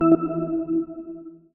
UIMisc_Forward Positive 02.wav